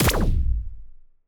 x1_battle_cyber_hit_3.wav